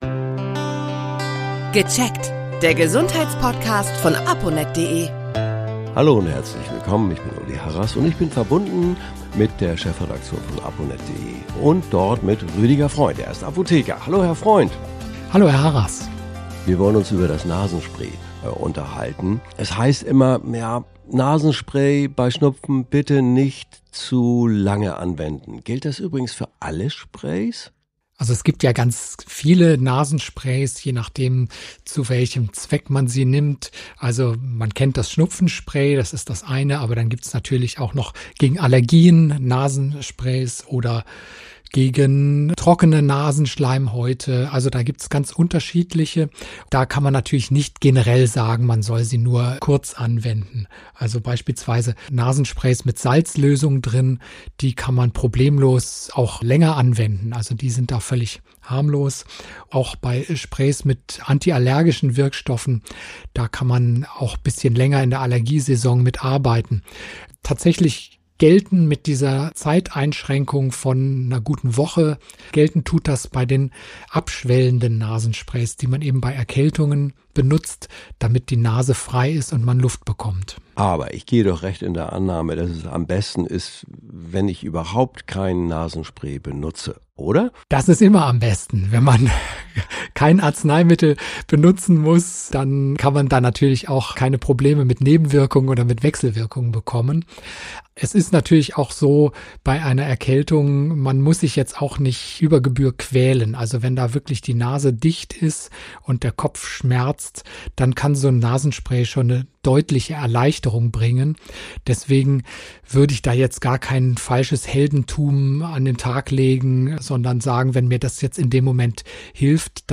Wie man den umgeht, erklärt ein Apotheker.